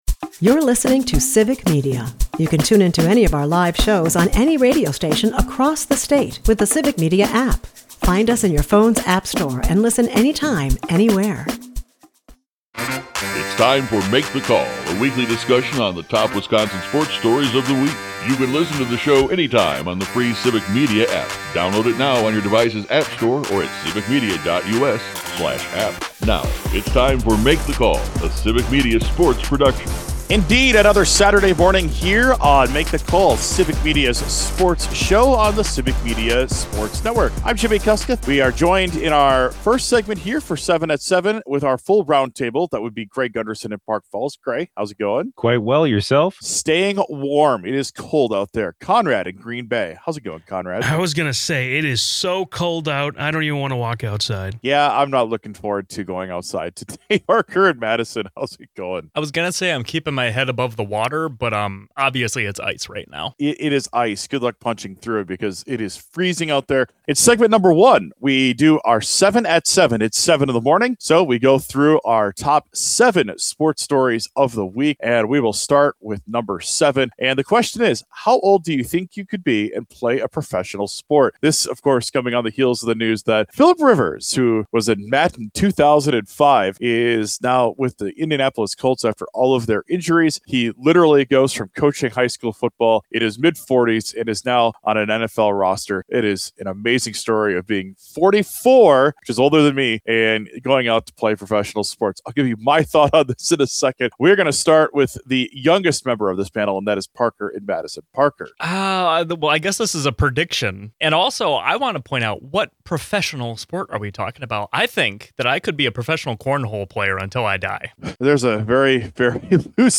The roundtable discusses the Packers as they march into Denver with a statement win in their eyes. Meanwhile Badger hoops got embarrassed in Nebraska.